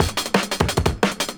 Cutter 175bpm.wav